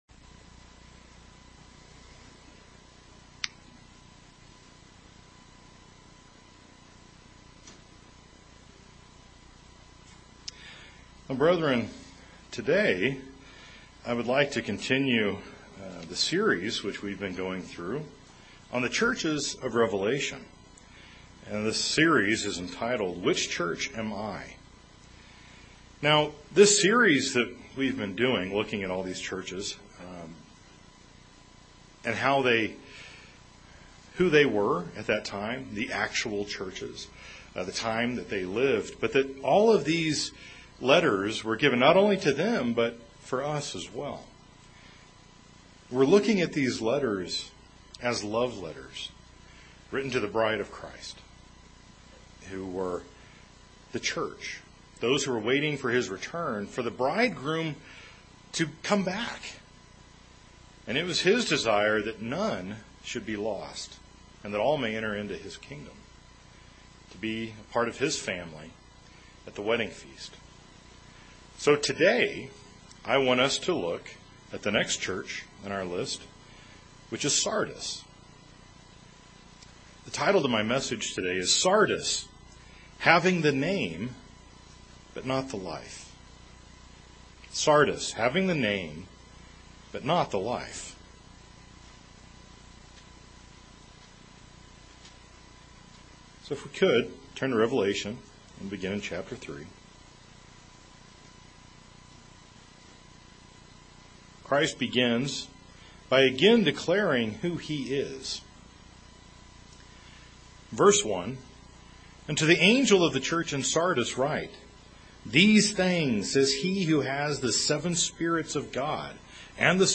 This sermon is the sixth in the series of "Which Church Am I?".